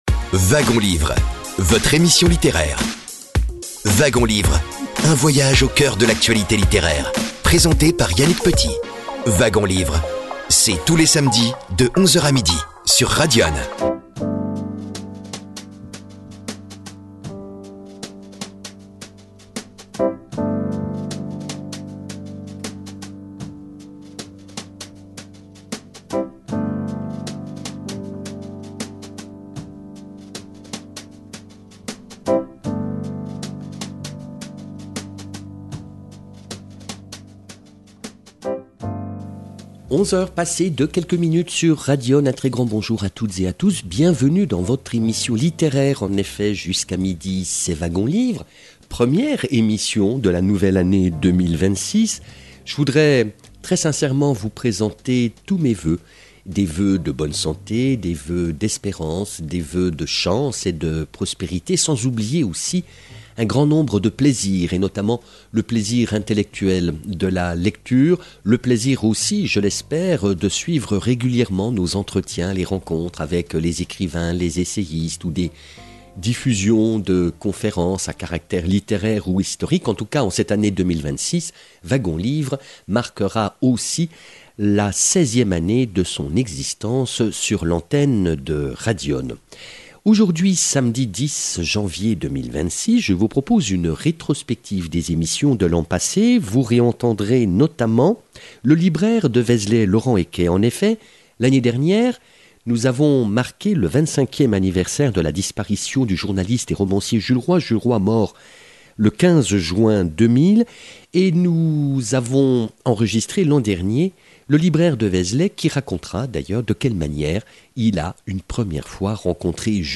Nous vous proposons des extraits d’émissions diffusées durant 2025 sur l’antenne de Radyonne FM.